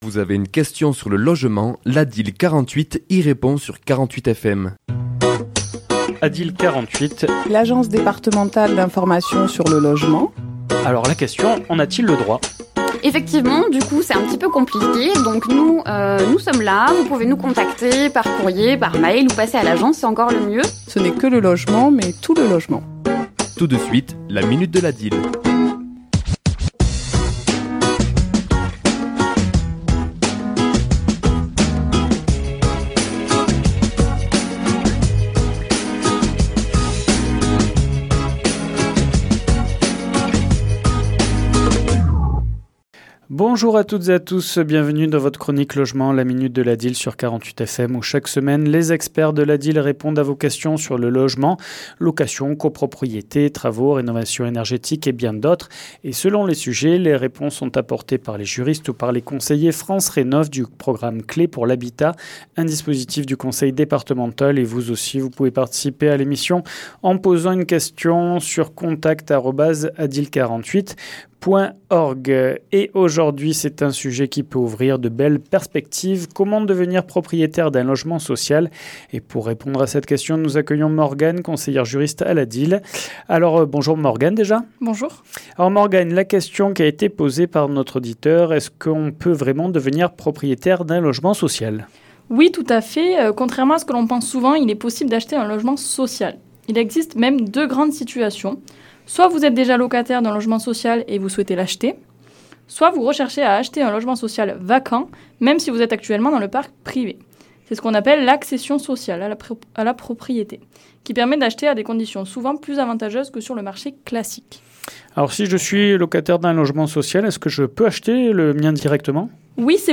ChroniquesLa minute de l'ADIL
Chronique diffusée le mardi 31 mars à 11h et 17h10